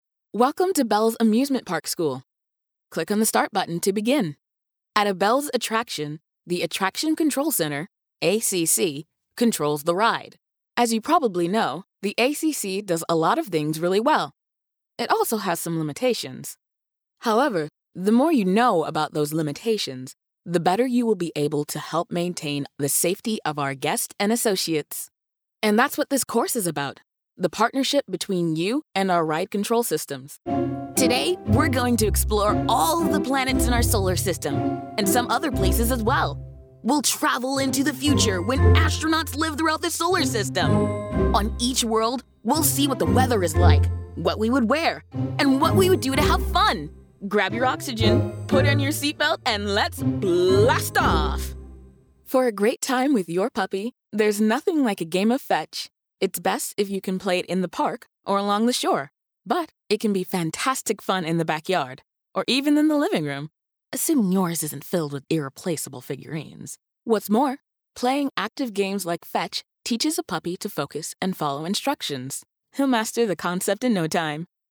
A Warm Bubby Friend
eLearning Demo
General American